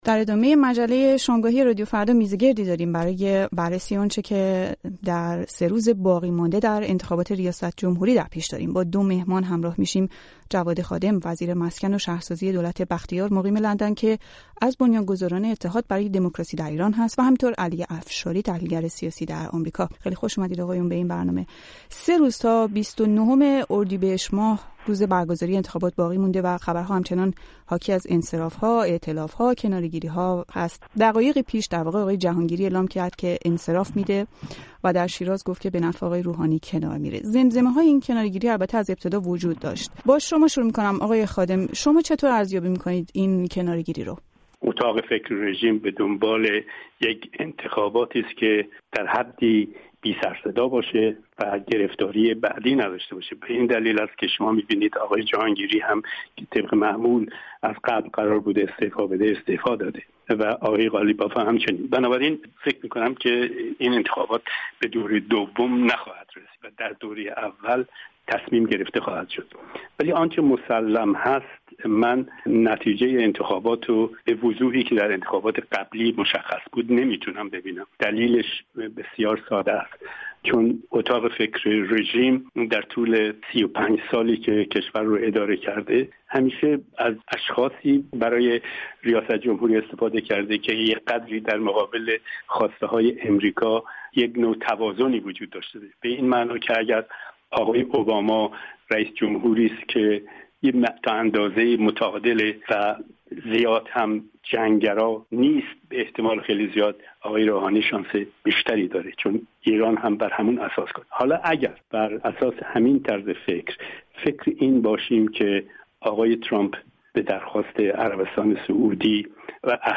میزگردی داریم برای بررسی آنچه در روزهای باقی‌مانده تا انتخابات ریاست‌جمهوری در پیش رو.